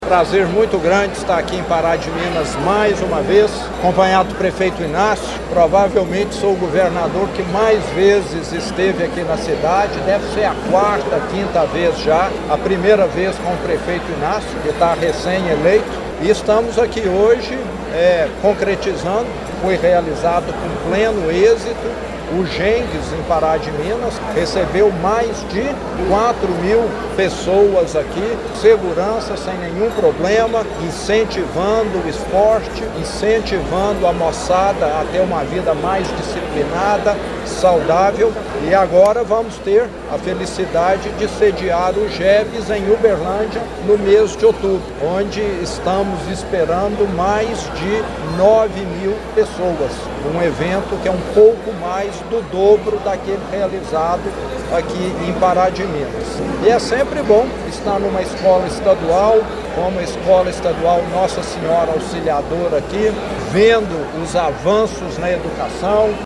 O evento ocorreu na quadra poliesportiva da Escola Estadual Nossa Senhora Auxiliadora, no bairro São Cristóvão, com a presença do governador do Estado de Minas Gerais, Romeu Zema, que ressaltou a importância de Pará de Minas para o desporto estudantil.